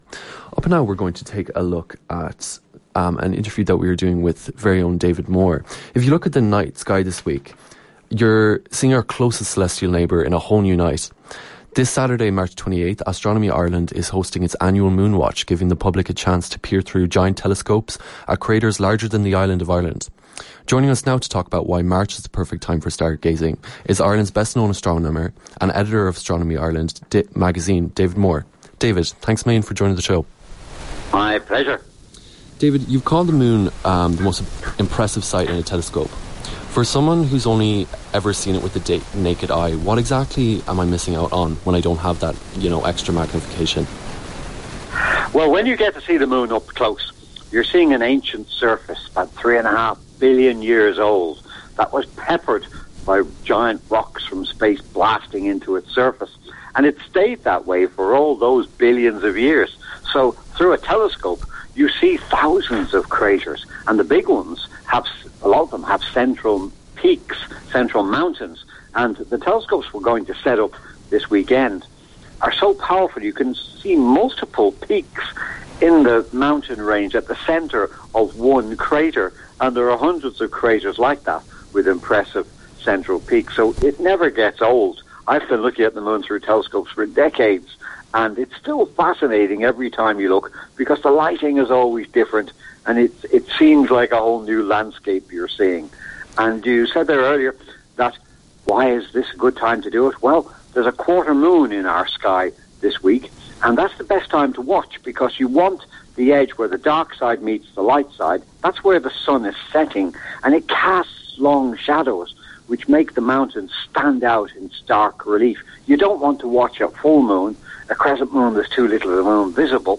RADIO :